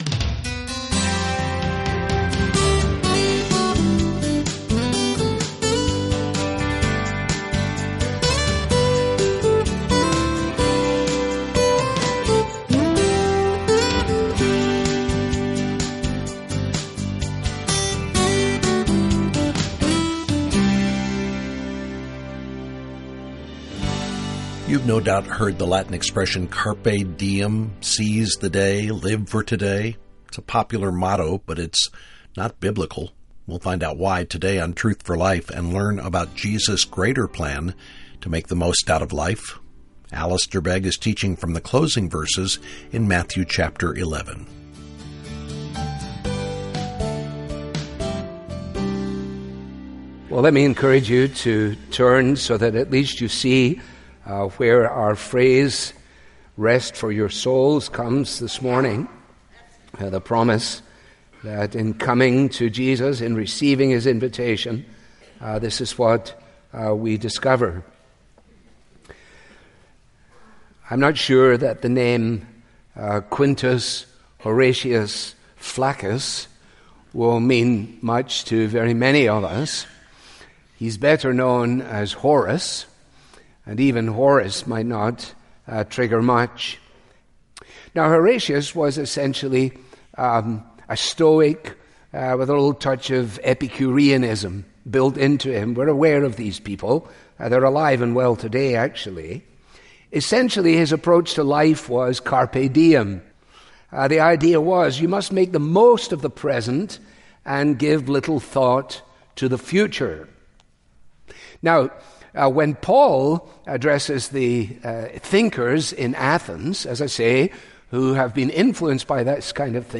Bible Teaching